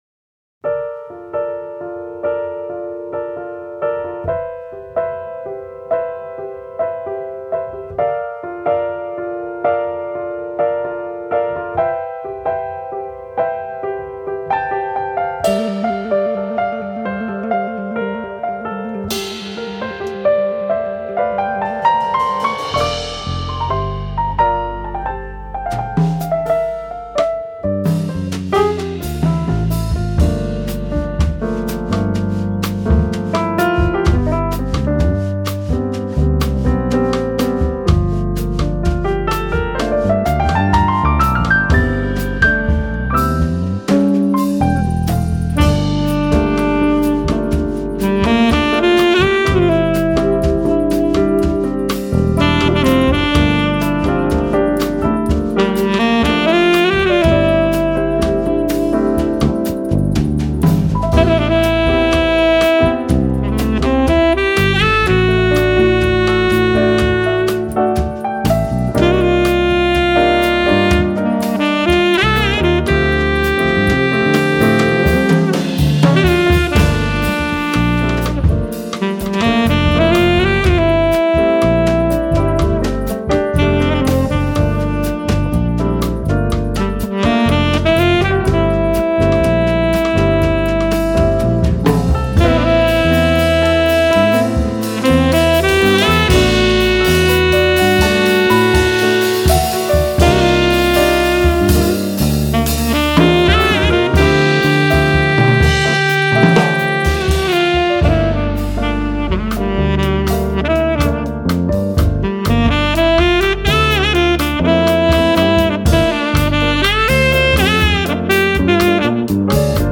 sax tenore
piano
basso elettrico, contrabbasso
batteria